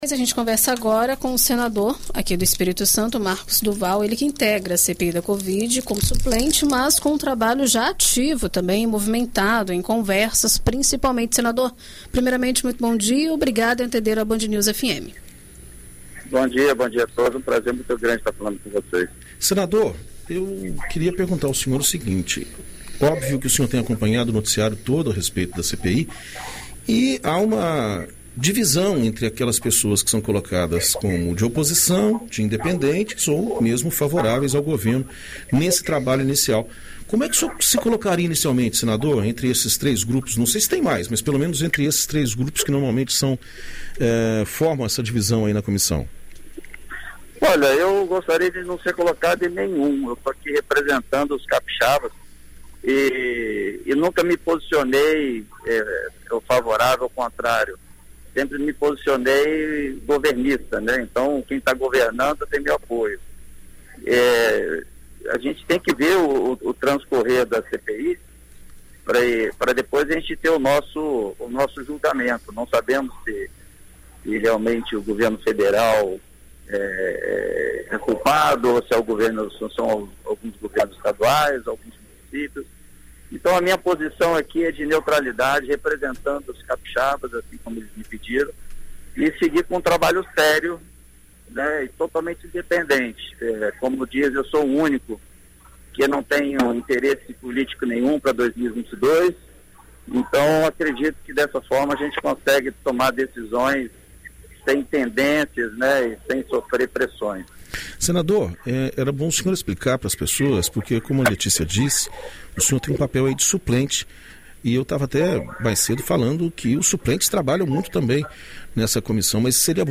Em entrevista à BandNews FM Espírito Santo nesta terça-feira (27), o senador Marcos do Val (Podemos-ES), que é suplente na comissão, conversa sobre a instauração da CPI e as definições prévias dos senadores.